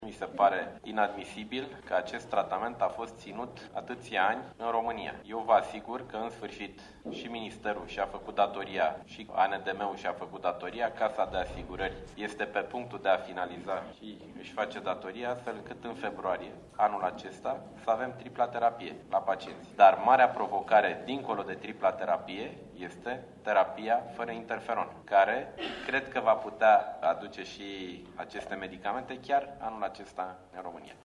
Ministrul Sanatatii, Nicolae Banicioiu afirma ca tripla terapie pentru bolnavii care sufera de hepatita C va fi accesibila in luna februarie.